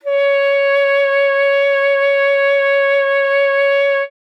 42e-sax09-c#5.wav